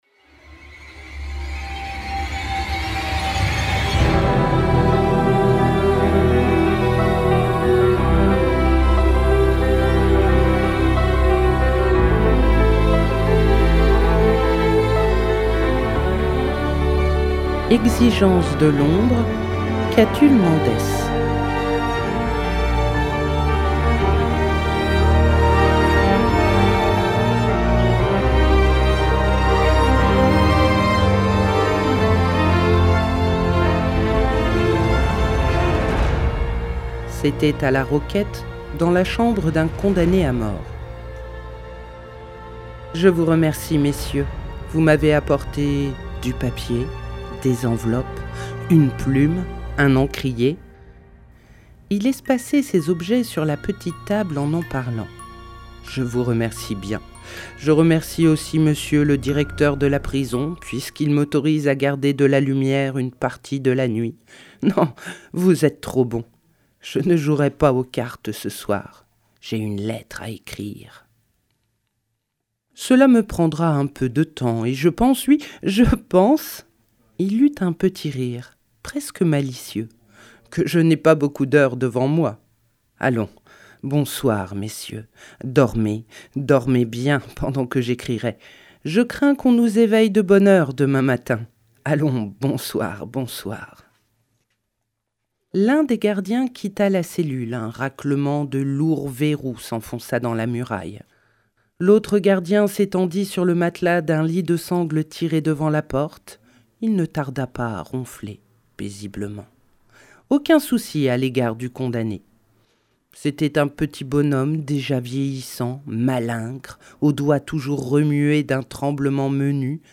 🎧 Exigence de l’ombre – Catulle Mendès - Radiobook